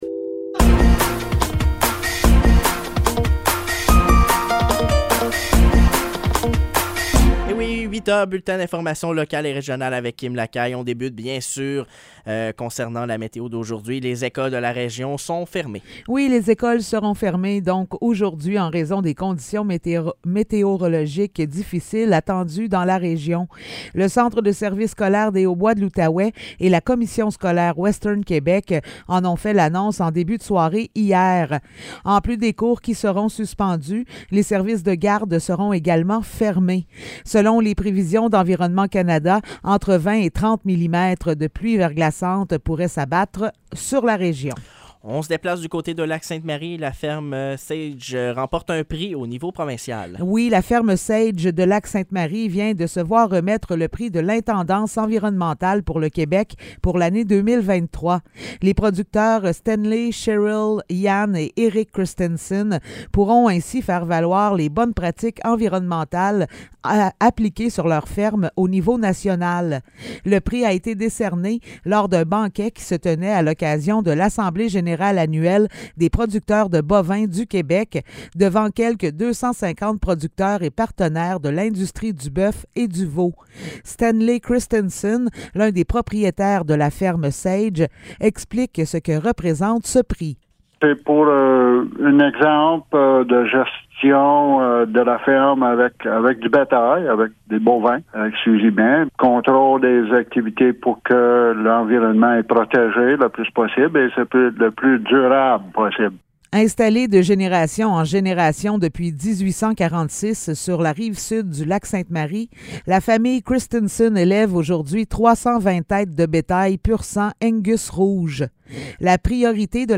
Nouvelles locales - 5 avril 2023 - 8 h